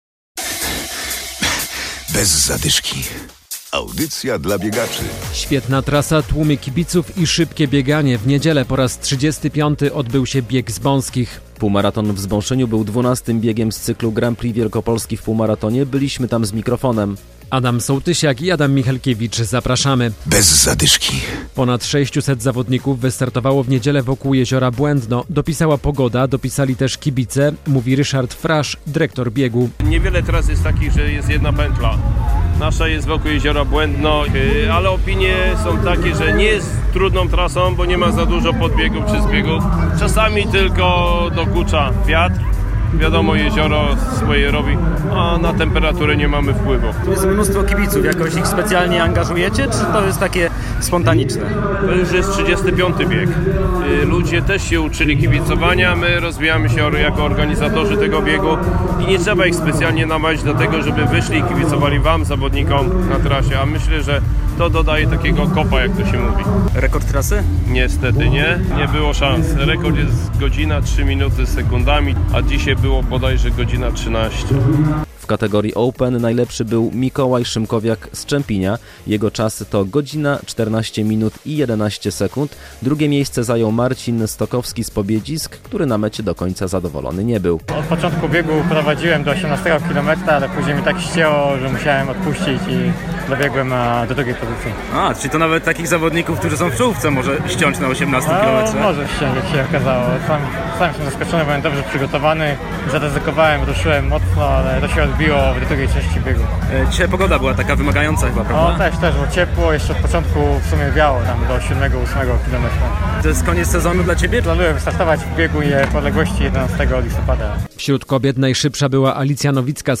Relacja ze Zbąszynia z 35. Biegu Zbąskich!